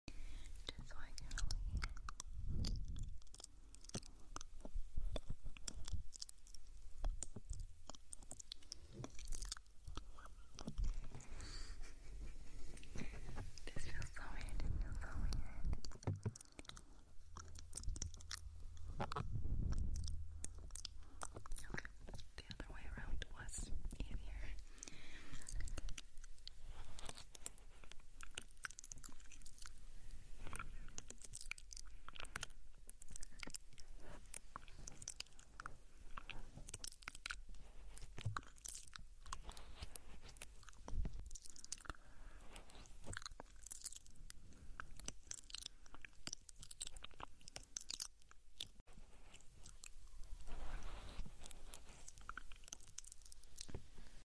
Ear licks and mouth sounds sound effects free download
Ear licks and mouth sounds for relaxation